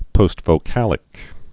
(pōstvō-kălĭk)